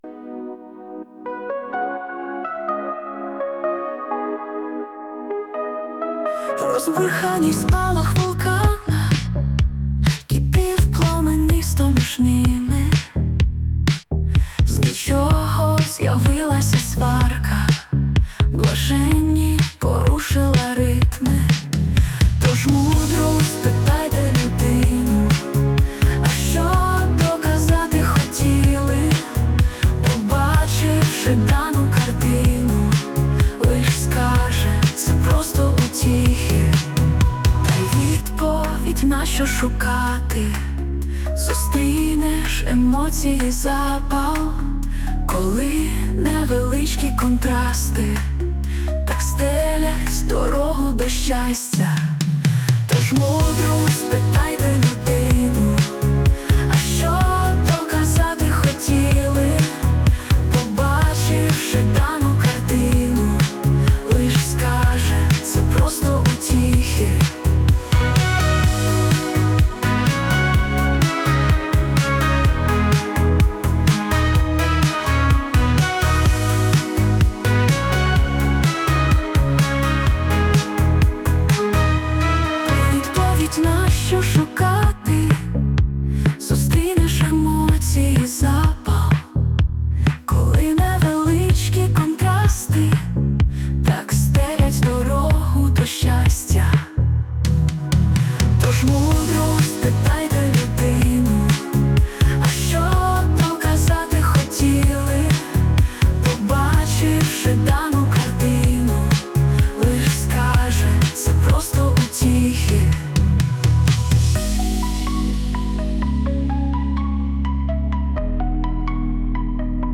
Текст - автора, мелодія і виконання - ШІ
СТИЛЬОВІ ЖАНРИ: Ліричний